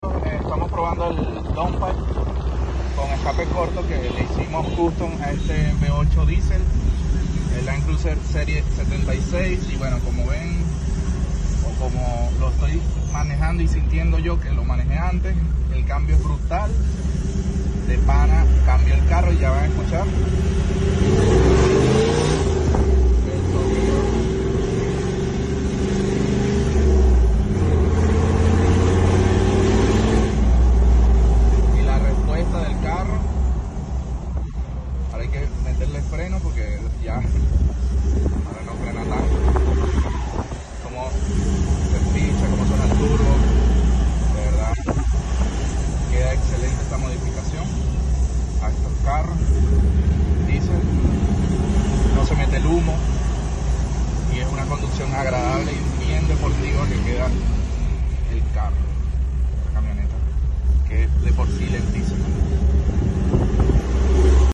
Diseño de Downpipe y escape sound effects free download
Y aumentando el sonido del turbo.
It also boosts the turbo sound.